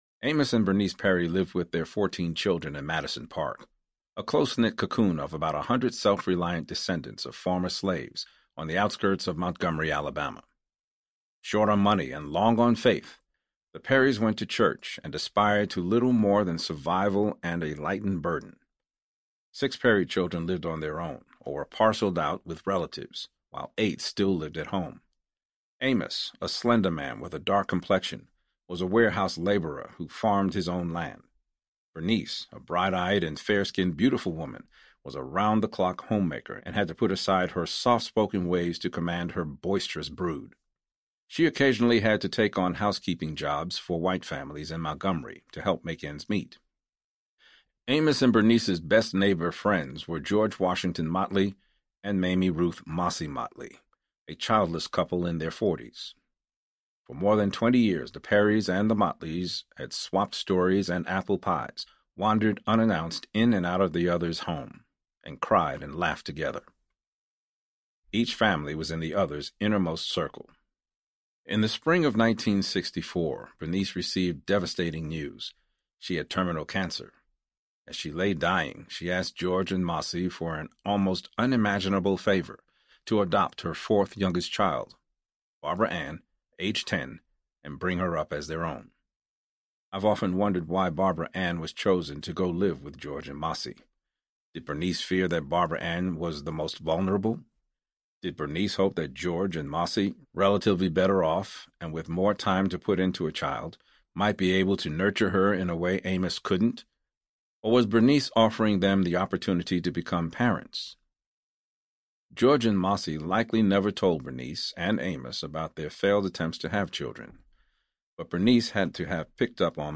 Madison Park Audiobook
7.9 Hrs. – Unabridged